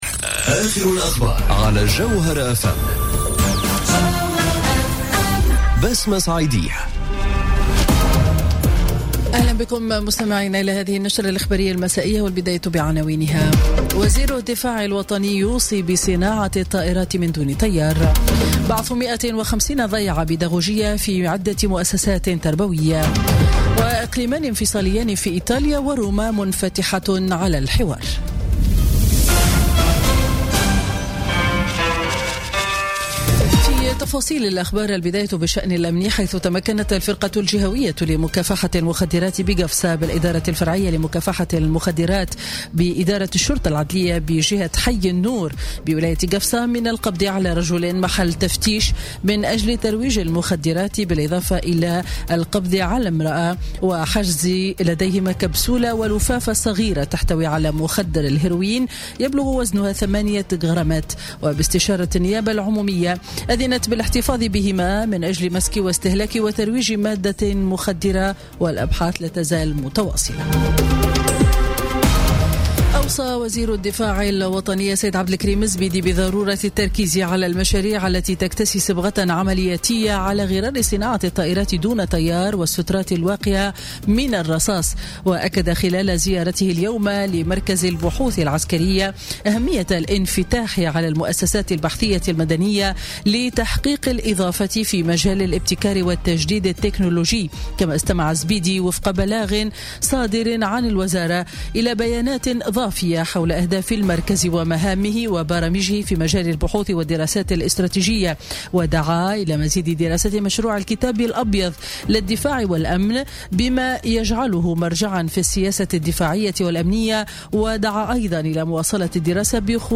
نشرة أخبار السابعة مساء ليوم الثلاثاء 24 أكتوبر 2017